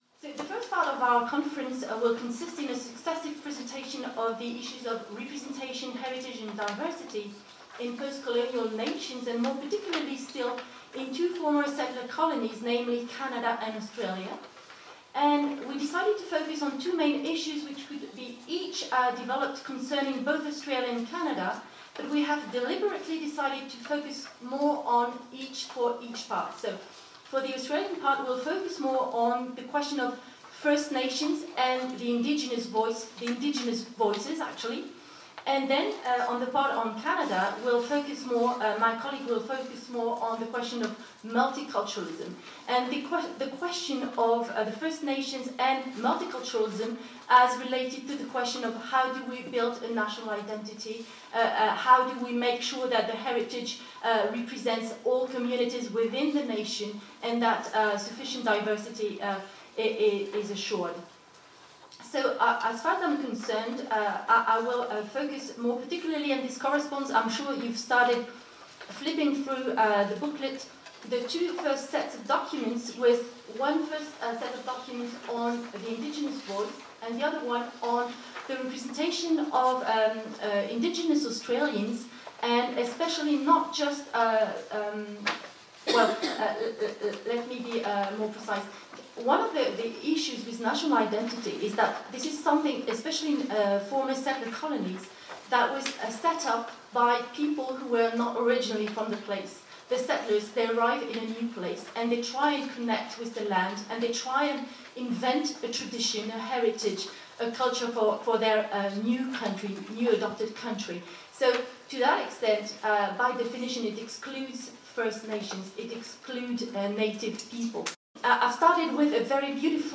[Conférence]